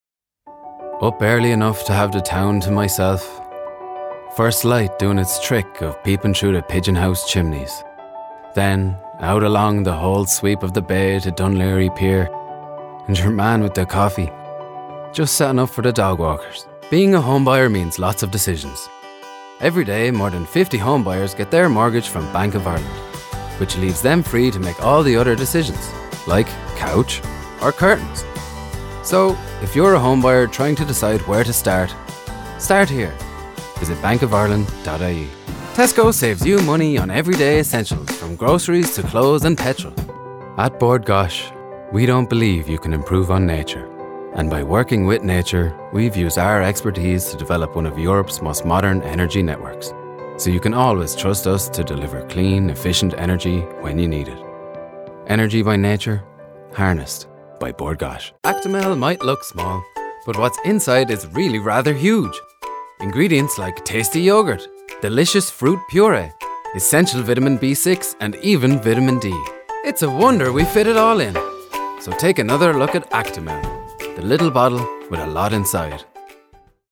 Male
20s/30s, 30s/40s
Irish Midlands, Irish Neutral